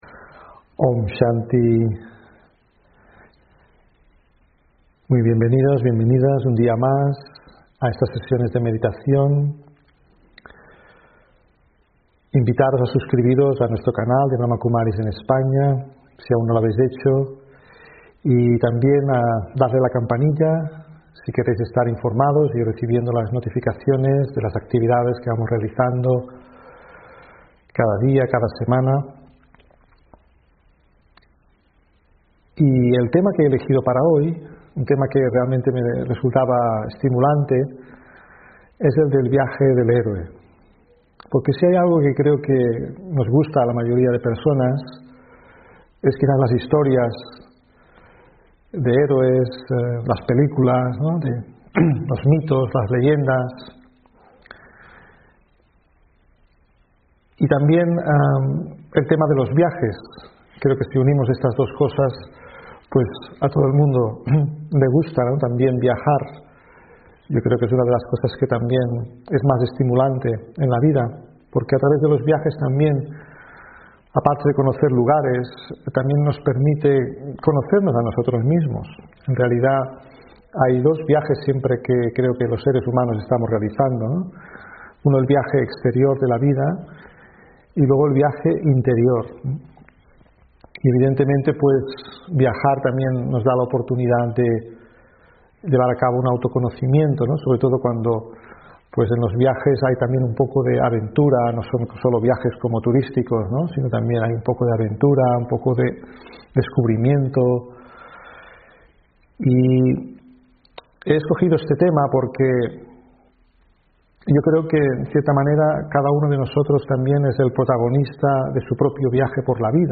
Meditación Raja Yoga de la mañana Conquistar la mente 9 (2 Diciembre 2020)